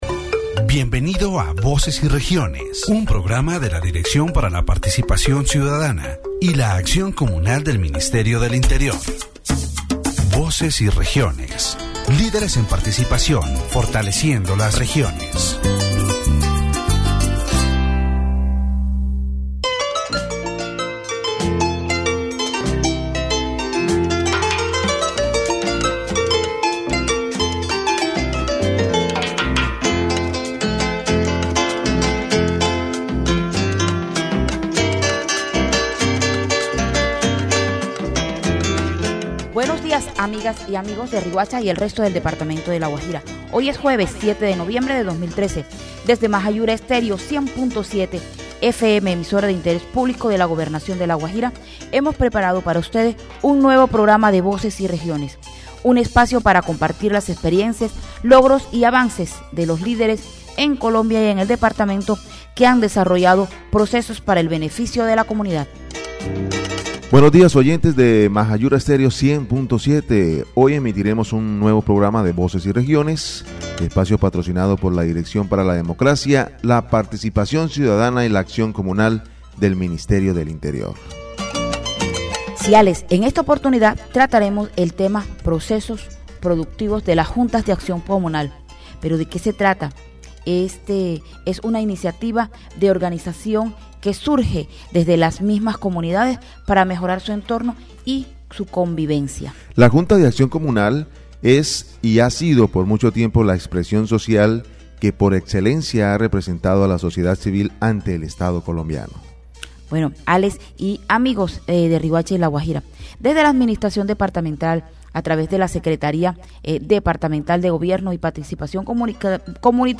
The radio program "Voices and Regions," broadcast from Mahayura Estéreo 100.7 FM, focused on strengthening Community Action Boards in La Guajira. Discussions revolved around community organization and empowerment through productive projects.